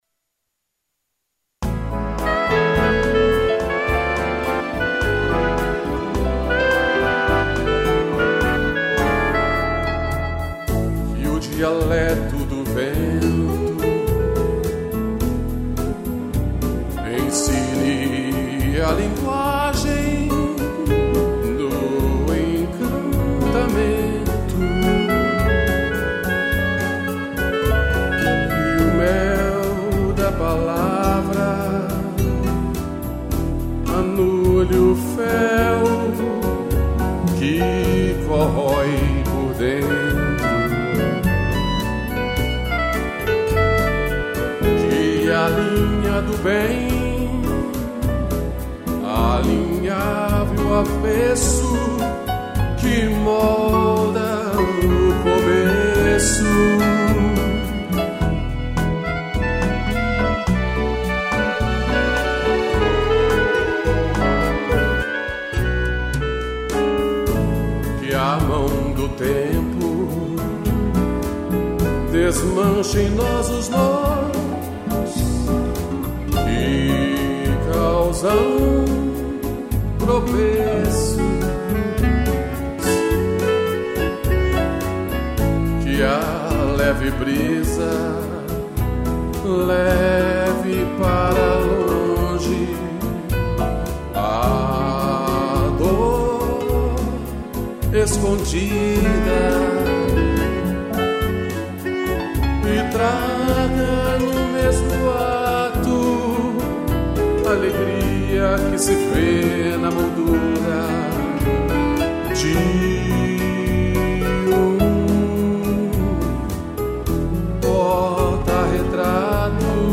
Voz
piano e clarinete